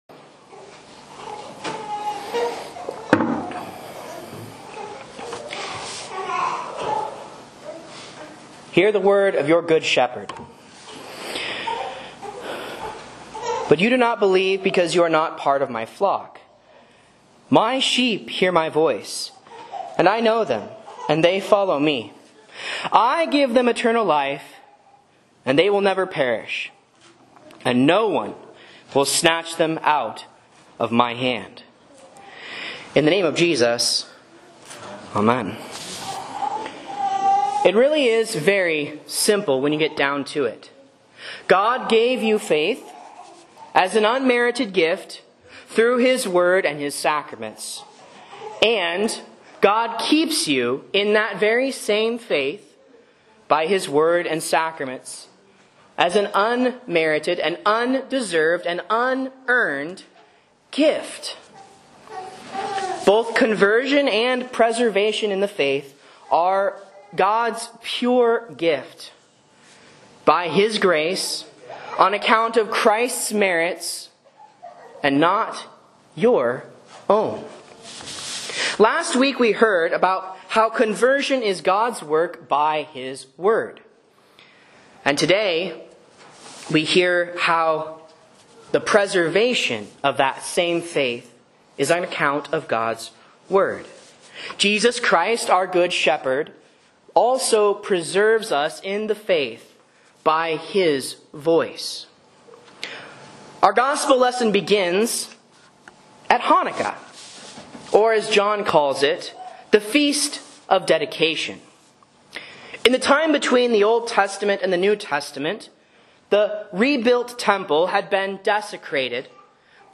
Sermons and Lessons from Faith Lutheran Church, Rogue River, OR
A Sermon on John 10.26-28 for Good Shepherd Sunday (Easter 4 C)